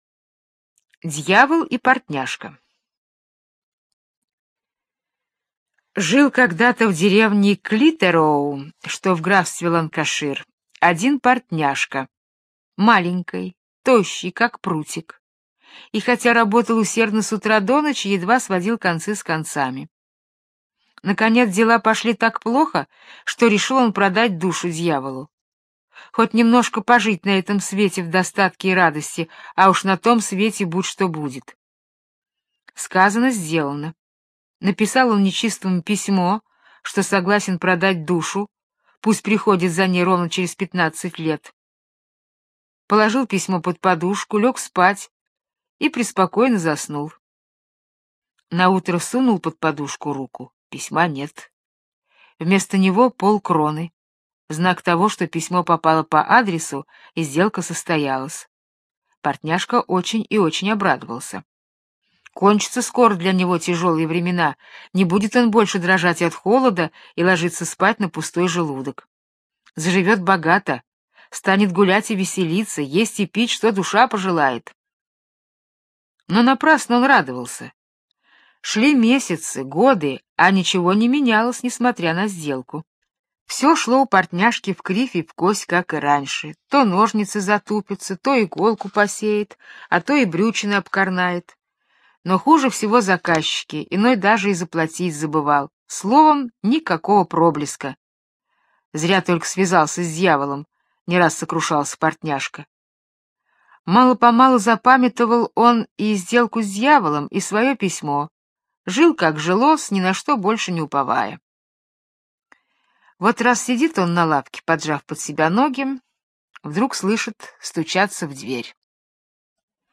Дьявол и портняжка - британская аудиосказка - слушать онлайн